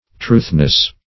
truthness - definition of truthness - synonyms, pronunciation, spelling from Free Dictionary Search Result for " truthness" : The Collaborative International Dictionary of English v.0.48: Truthness \Truth"ness\, n. Truth.